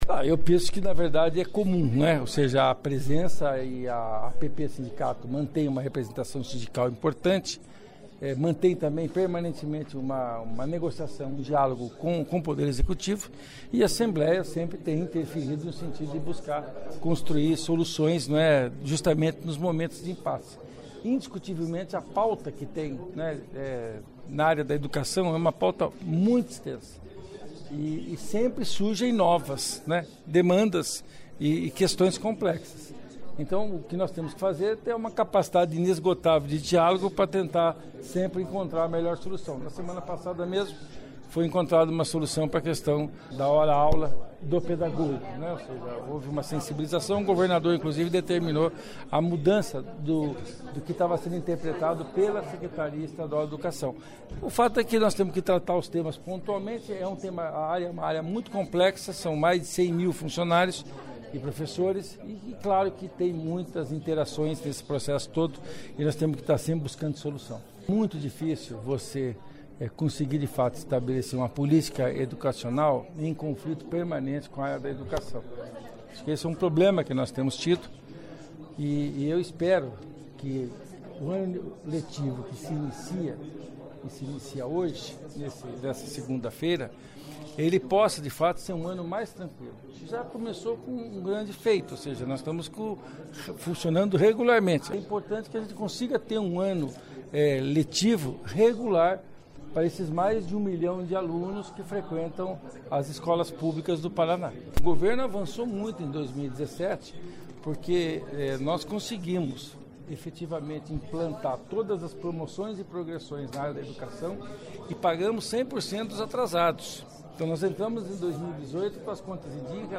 Ouça a entrevista com o líder do Governo.